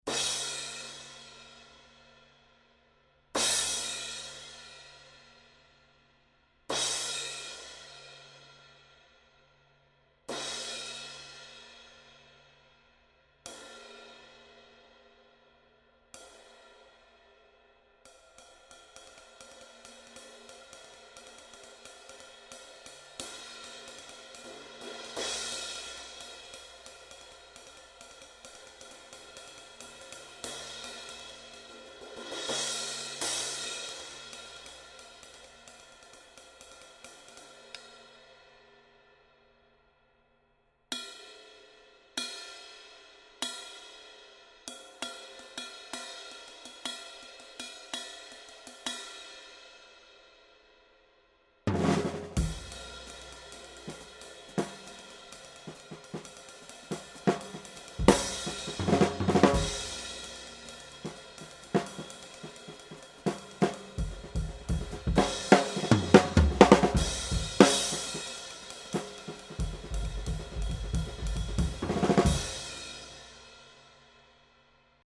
18" Trad Thin Crash 1296g